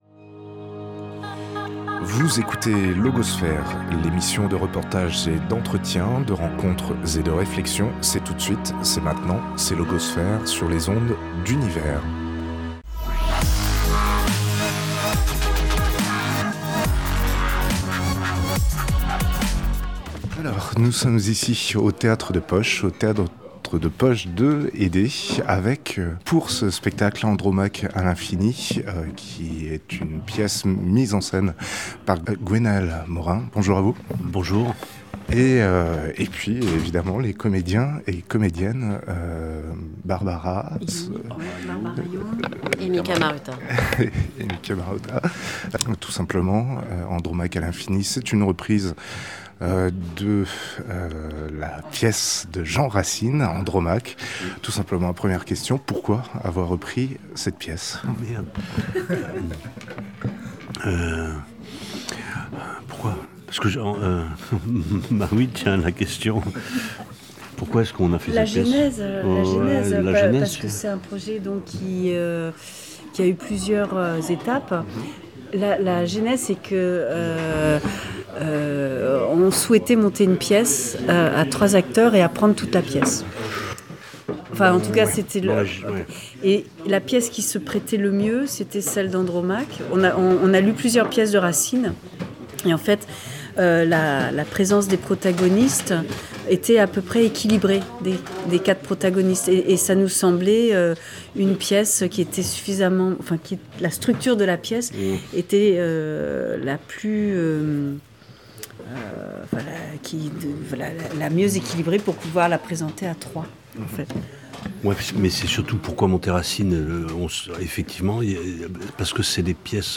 Entretien avec les membres de la Compagnie Le Théâtre Permanent autour de leur spectacle « Andromaque à l’infini«